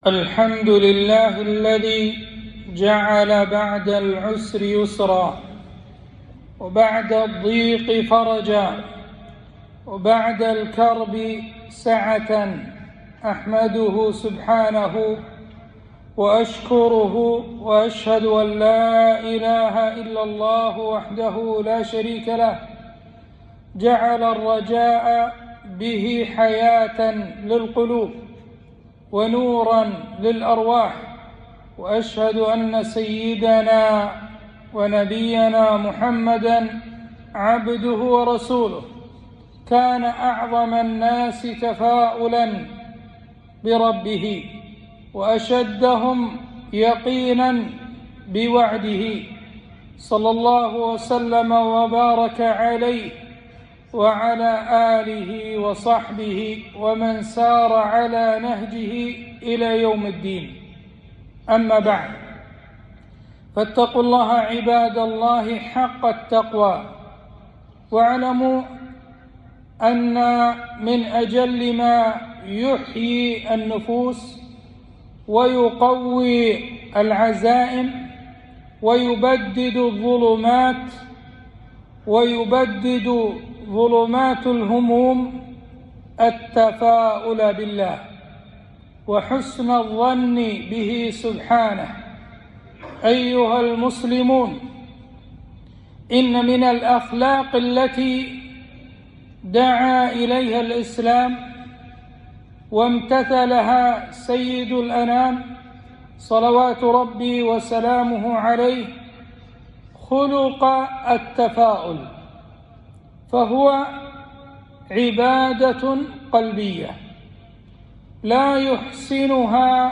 خطبة - التفاؤل وحسن الظن بالله